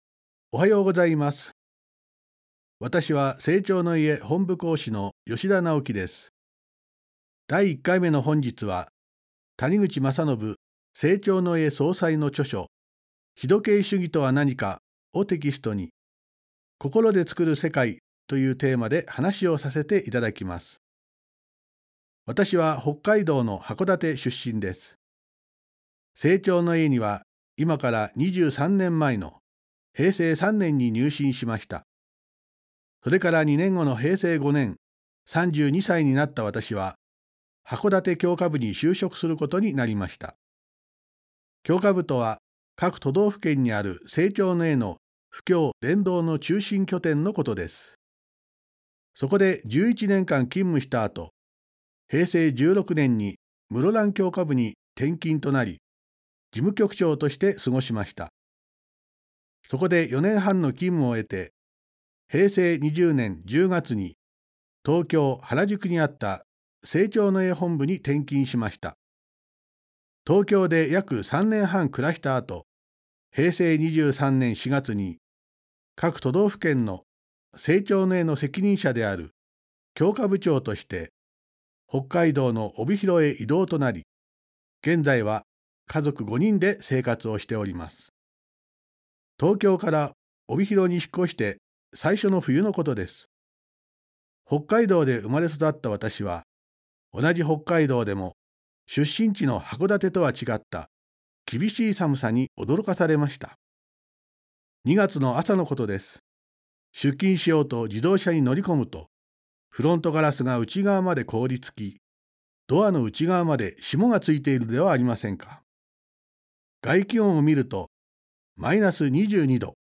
生長の家がお届けするラジオ番組。